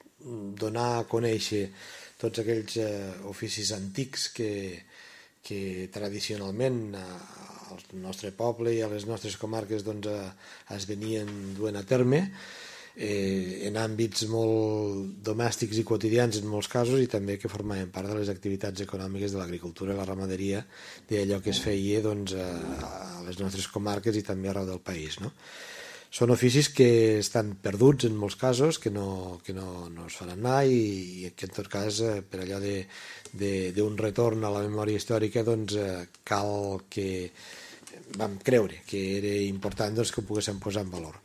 El president de la Diputació de Lleida, Joan Reñé, ha explicat que la fira pretén posar en valor i recuperar els oficis antics i artesans que tradicionalment es duen a terme als nostres pobles i comarques, tant de l’àmbit domèstic com també del nostre entorn agrícola i rural.